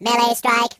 project_files/HedgewarsMobile/Audio/Sounds/voices/Robot/Firepunch2.ogg